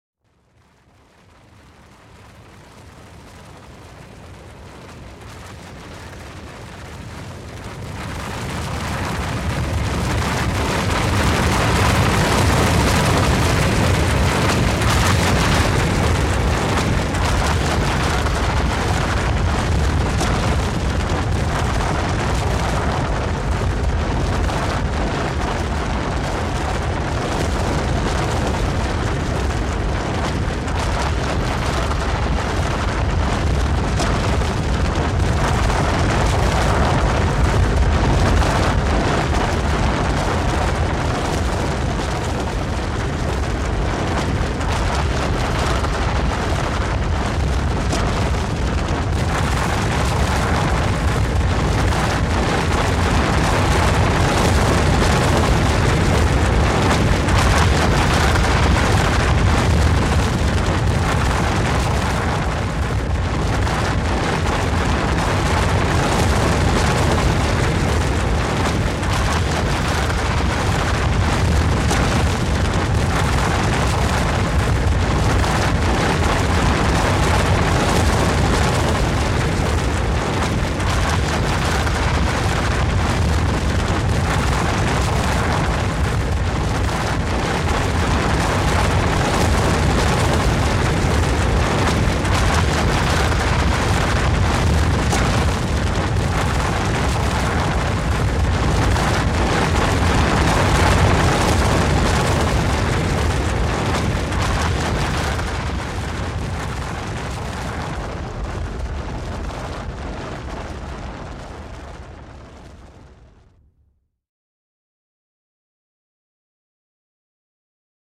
Здесь собраны разные варианты: от далеких раскатов до близкого схода снежной массы.
Долгое схождение лавины